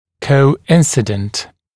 [kəu’ɪnsɪdənt][коу’инсидэнт]совпадающий (в т.ч. в пространстве)